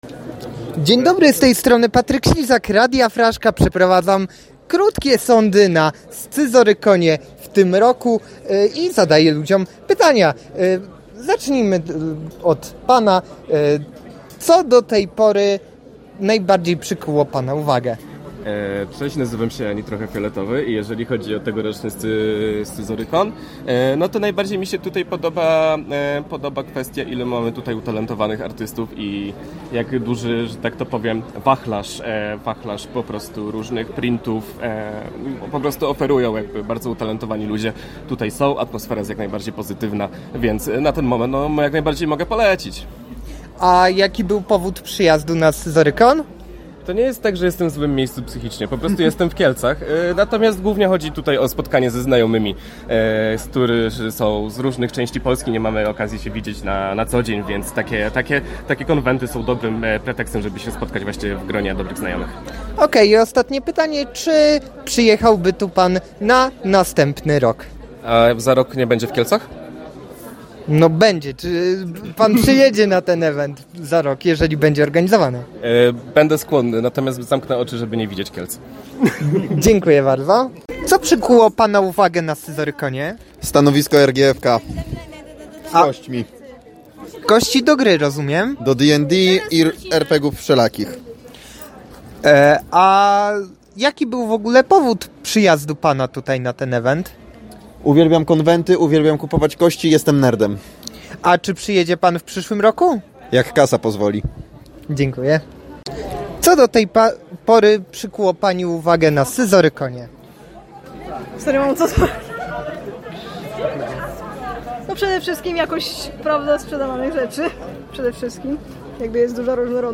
Atmosfera jest bardzo pozytywna – mówiła jedna z uczestniczek.
Dopiero zaczynam zwiedzanie, a już jestem pozytywnie zaskoczony – dodaje inny uczestnik.
Scyzorykon-uczestnicy.mp3